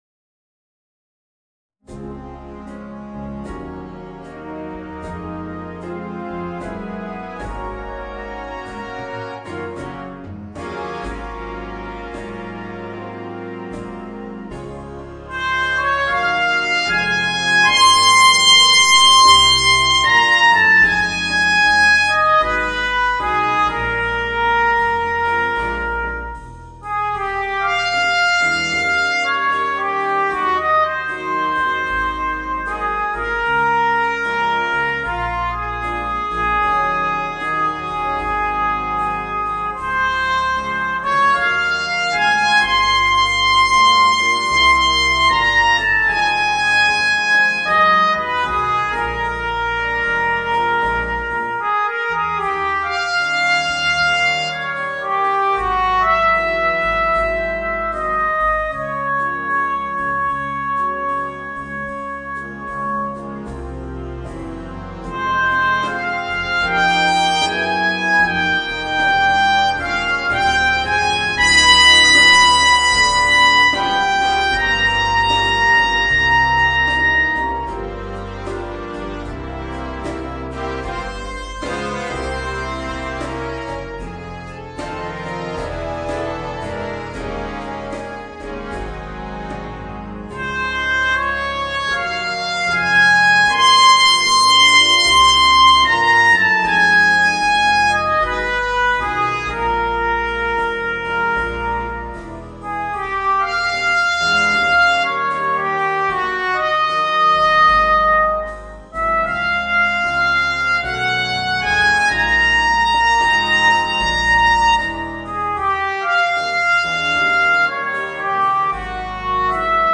Voicing: Alto Saxophone and Concert Band